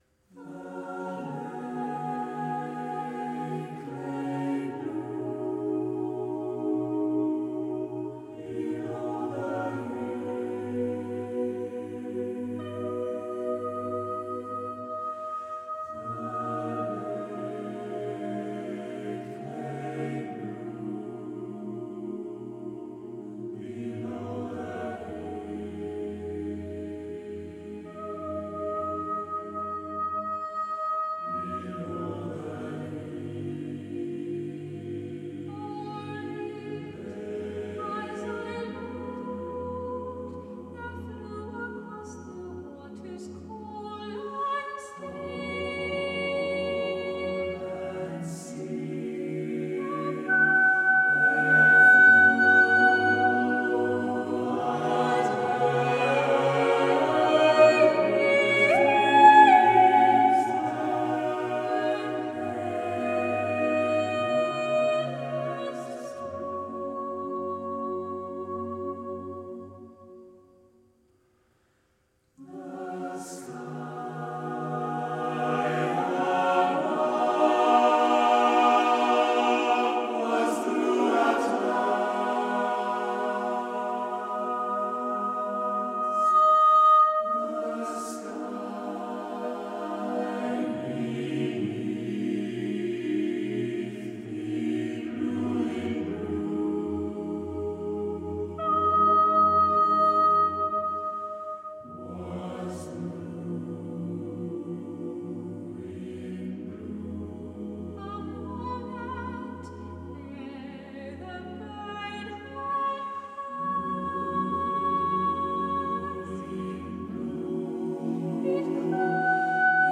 MU Chamber Choir performing The Blue Bird